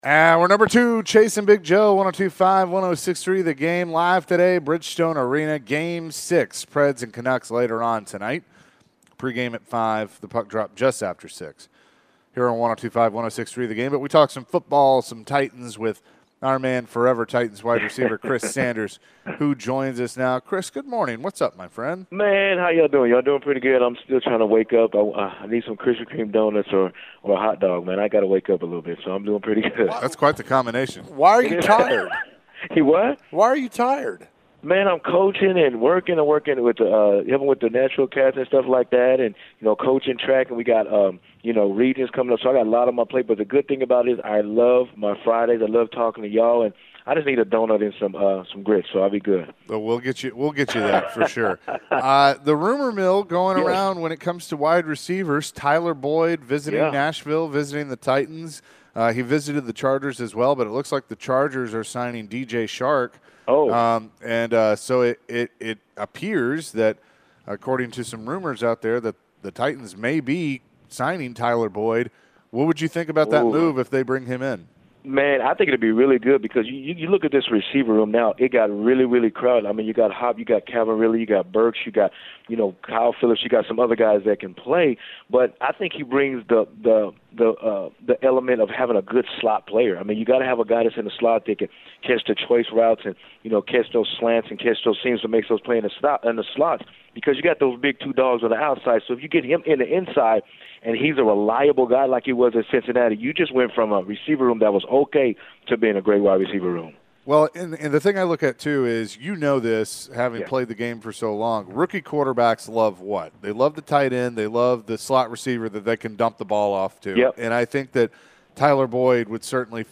Forever Titans wide receiver Chris Sanders joined the show. Chris discussed the NFL draft and his reaction to the Titans picks.